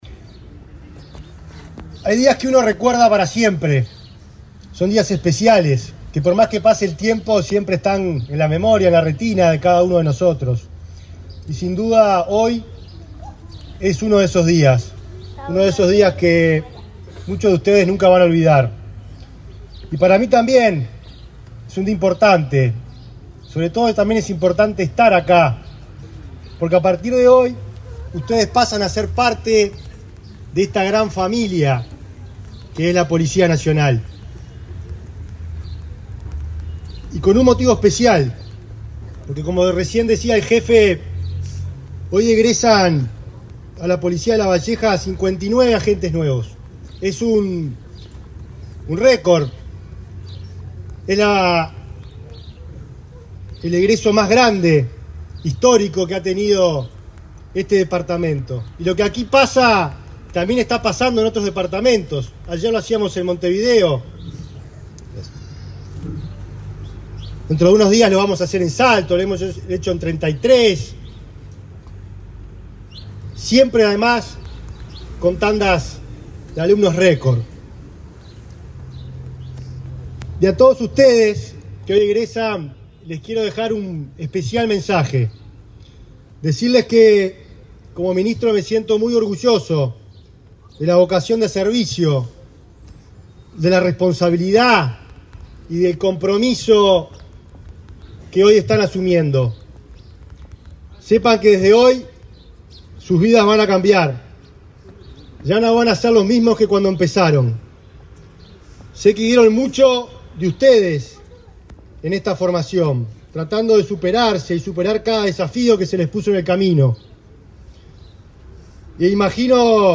Palabras del ministro del Interior, Nicolás Martinelli
Este jueves 15 en Lavalleja, el ministro del Interior, Nicolás Martinelli, participó en el acto de egreso de 59 efectivos y la presentación de obras